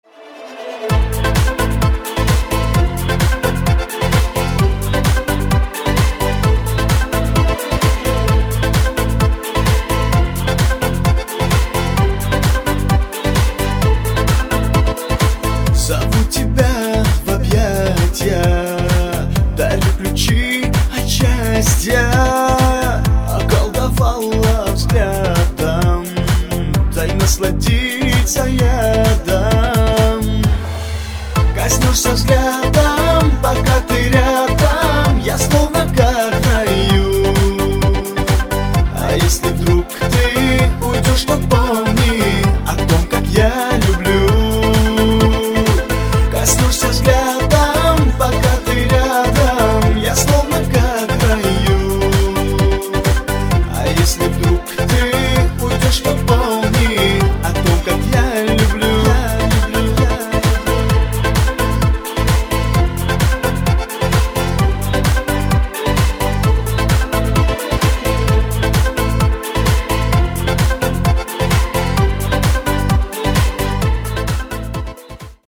• Качество: 320, Stereo
мужской вокал
русский шансон